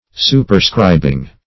Superscribing - definition of Superscribing - synonyms, pronunciation, spelling from Free Dictionary